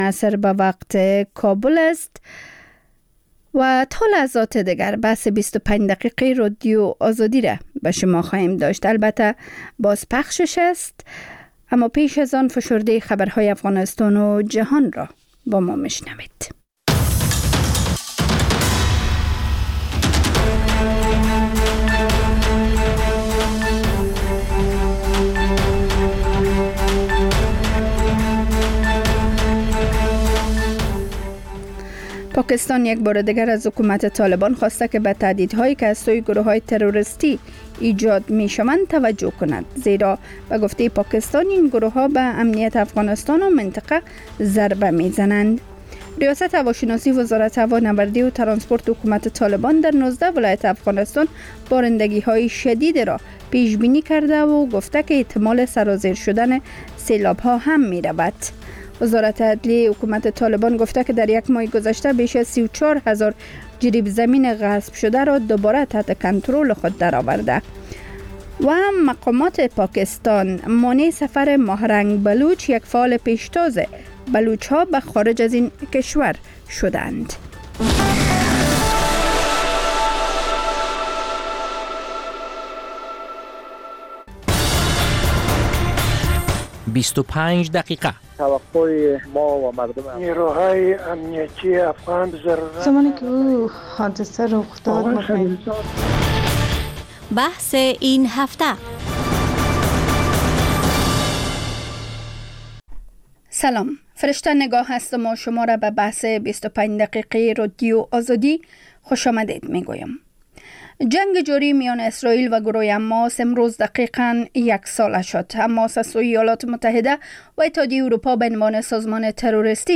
خبرهای کوتاه - میز گرد (تکرار)